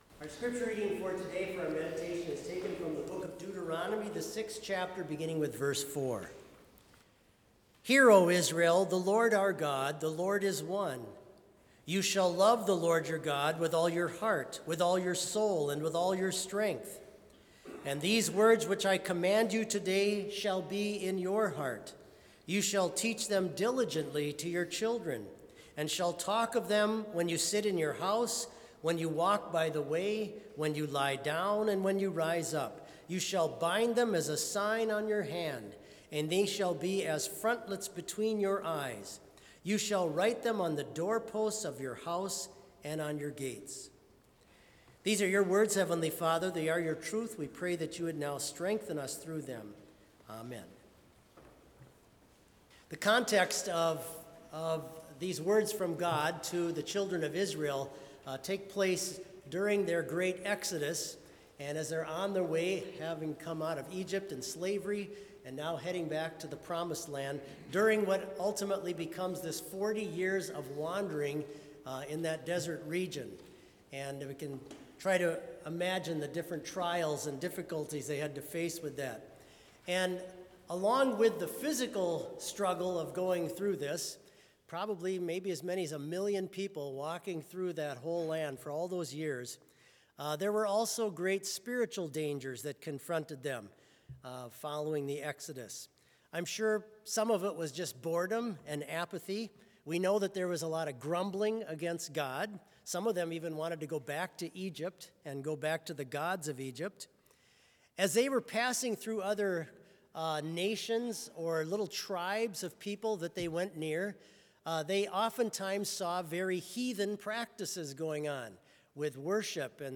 Complete service audio for Chapel - Thursday, January 23, 2025